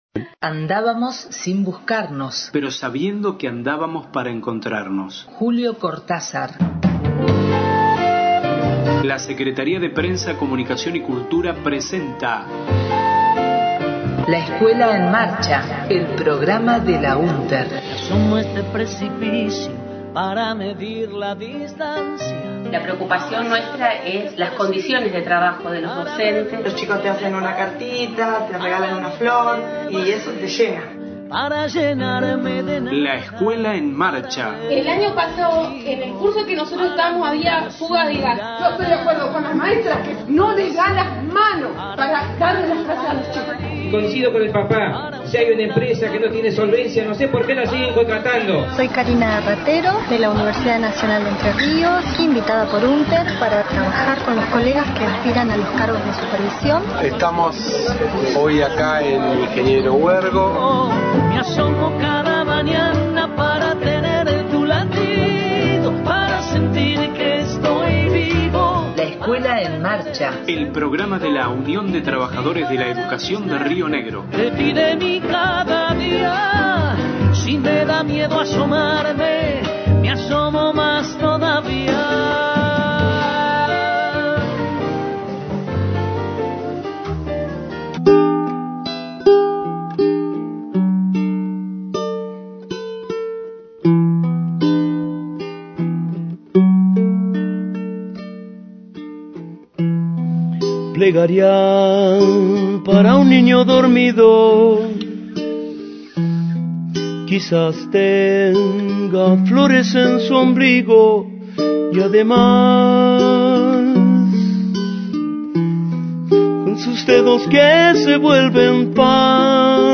Voces del Congresos Ordinario y Extraordinario de Unter, realizado en Las Grutas el 4 de diciembre •